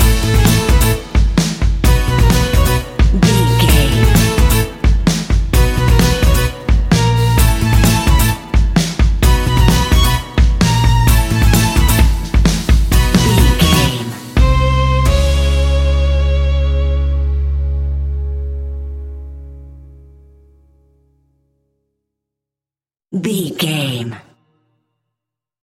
Aeolian/Minor
C#
acoustic guitar
mandolin
double bass
accordion